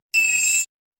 金切り声